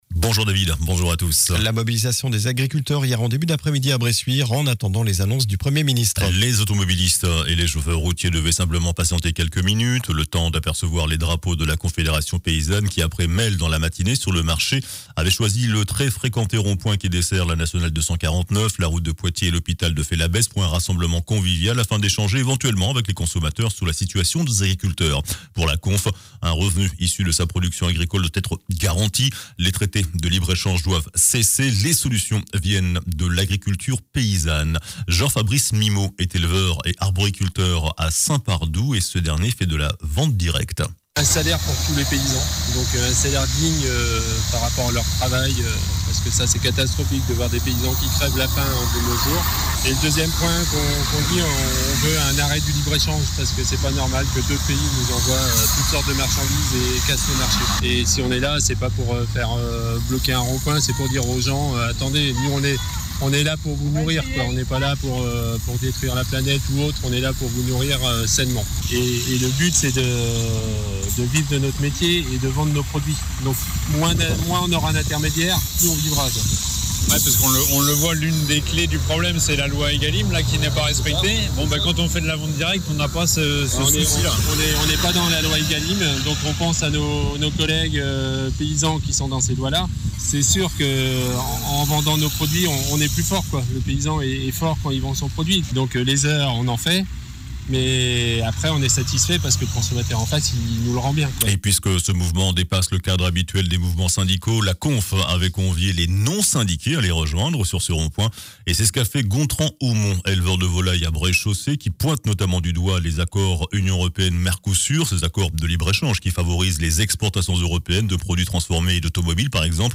JOURNAL DU SAMEDI 27 JANVIER